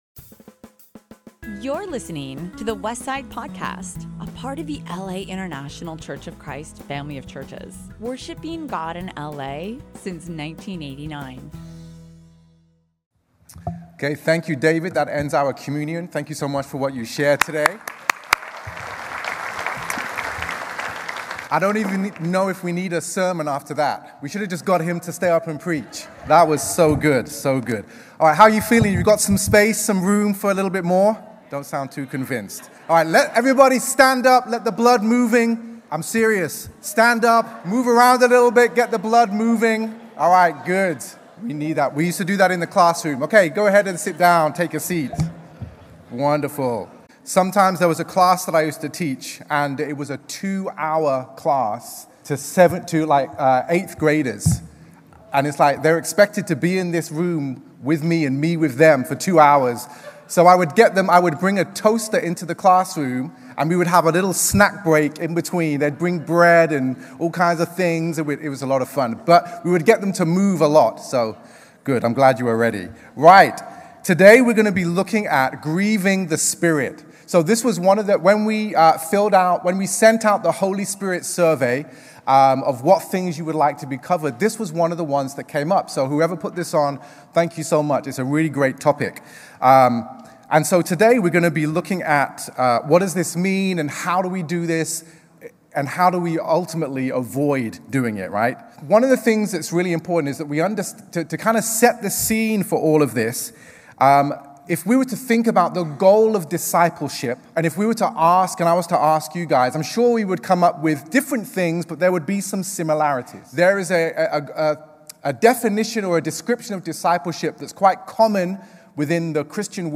Download Download Sermon Notes Grieving the Spirit.pdf Stop->Pray->Listen 1.